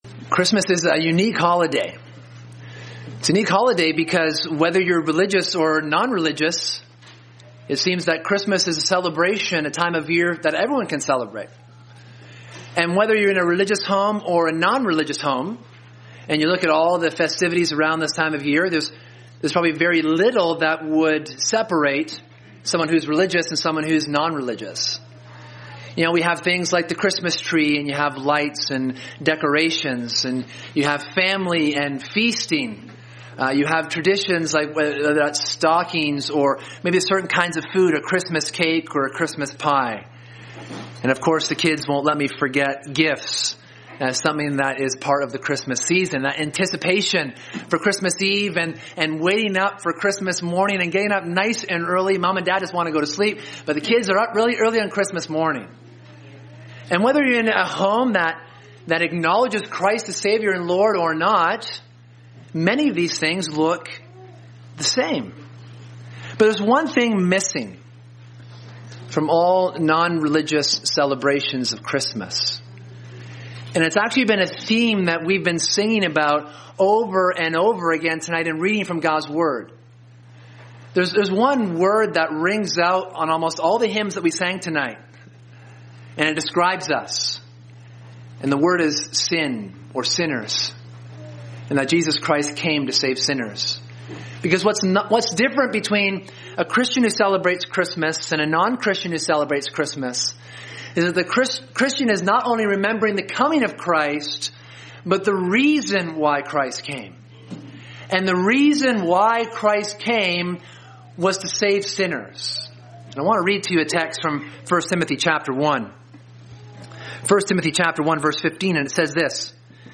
This message was preached on Christmas Eve 2018.